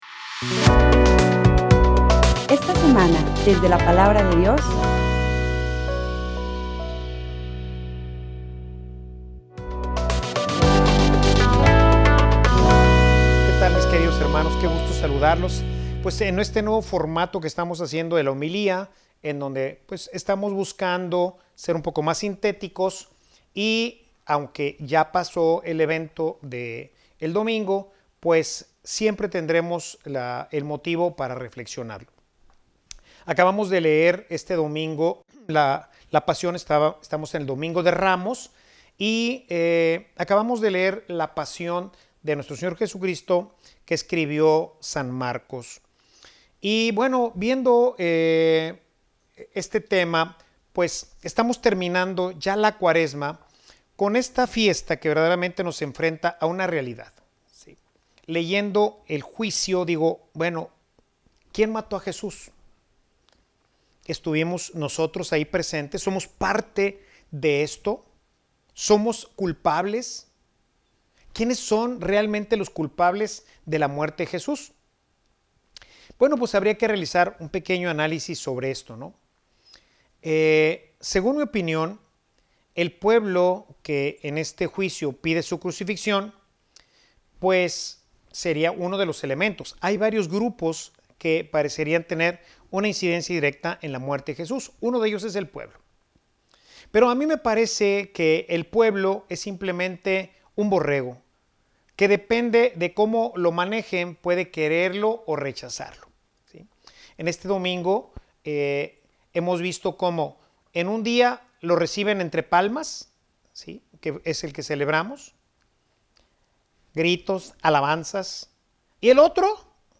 Homilia_Quien_mato_a_jesus.mp3